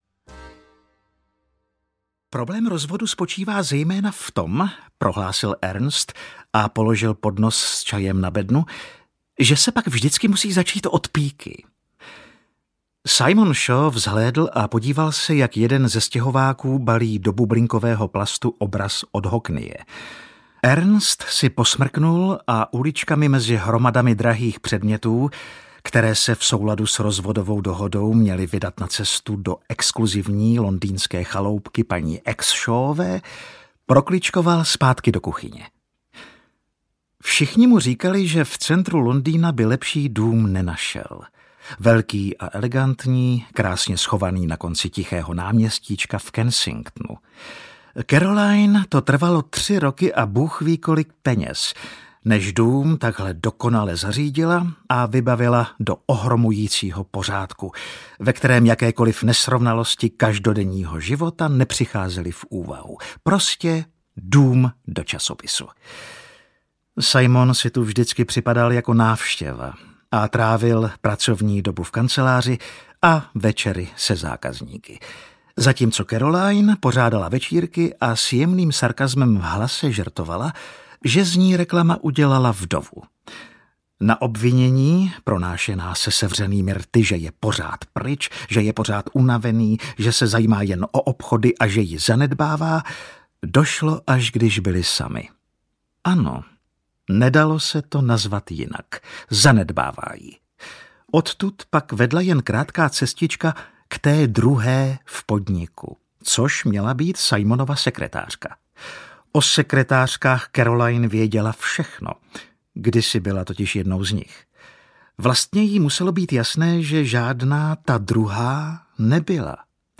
Audio knihaHotel Pastis
Ukázka z knihy
• InterpretAleš Procházka
hotel-pastis-audiokniha